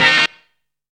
SAXERSTAB.wav